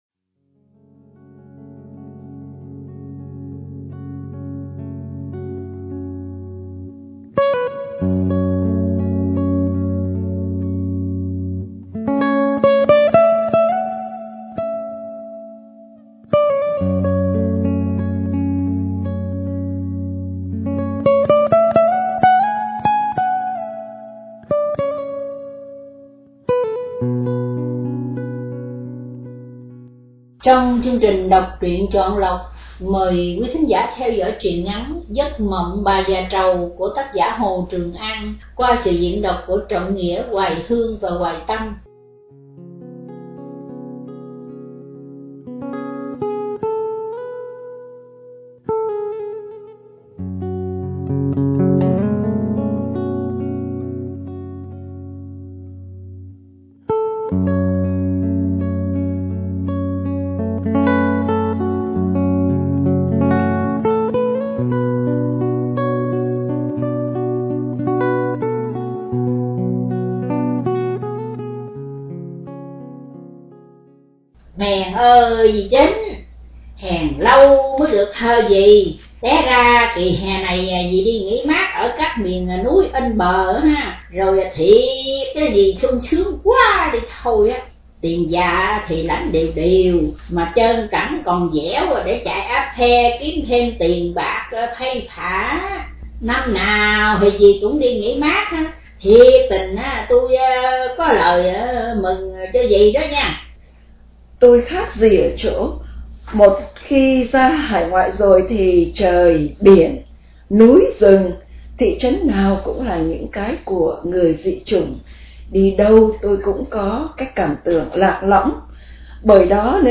Đọc Truyện Chọn Lọc – Giấc Mộng Bà Già Trầu – Tác Giả Hồ Trường An – Radio Tiếng Nước Tôi San Diego